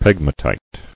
[peg·ma·tite]